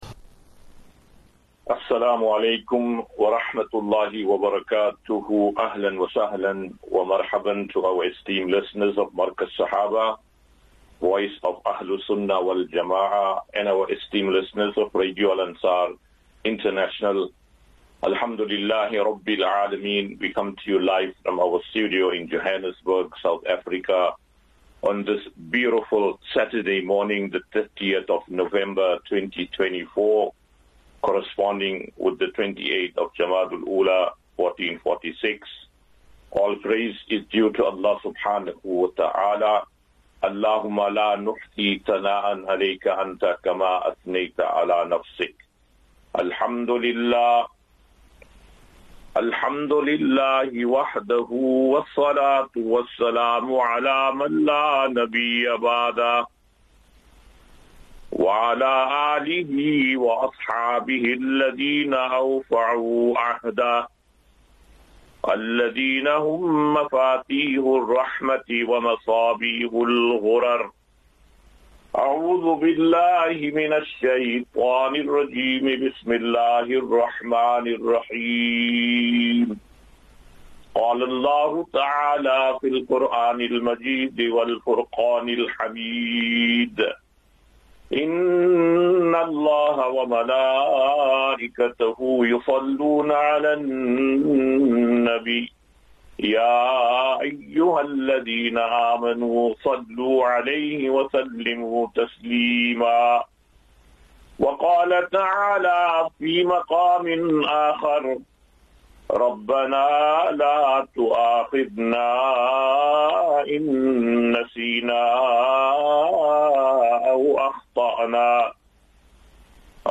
Part 1 QnA